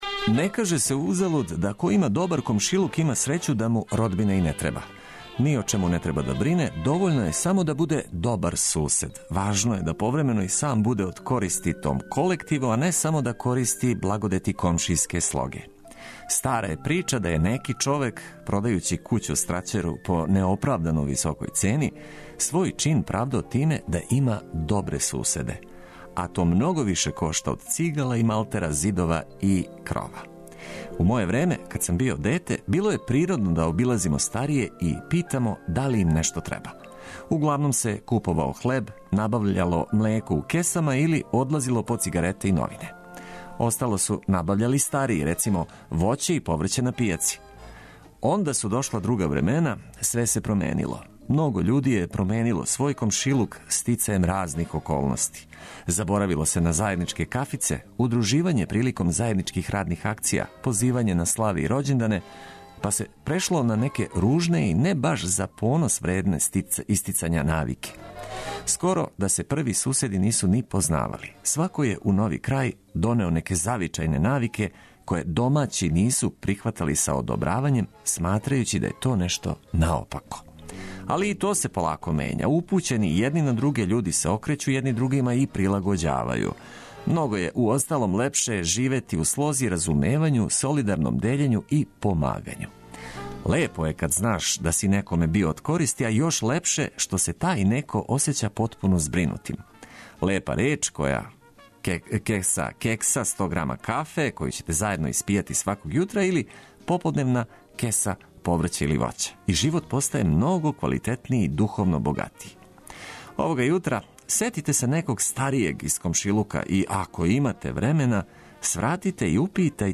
Нека недеља почне уз много музике и ведре теме.